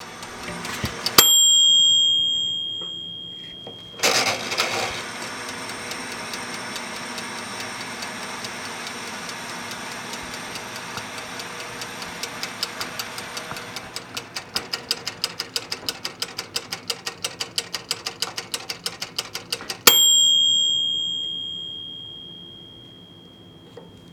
timer with ding